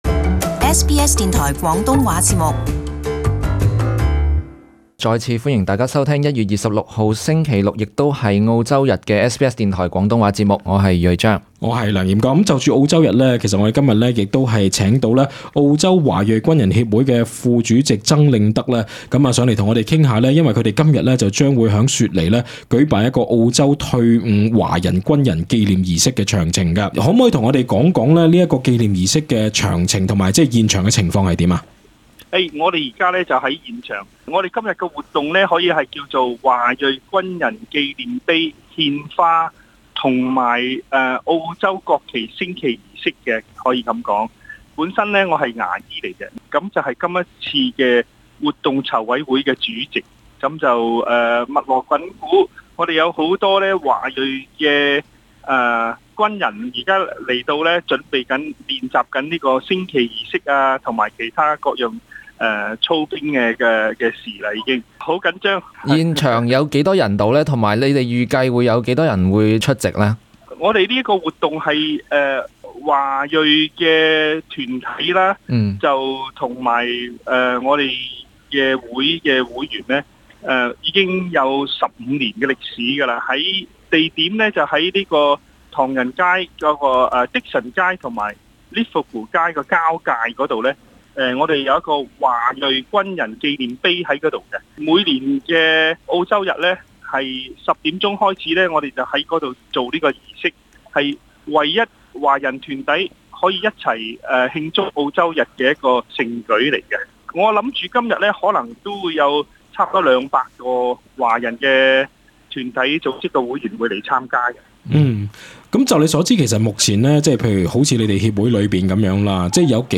【澳洲日專訪】全國唯一澳洲日華人軍人紀念儀式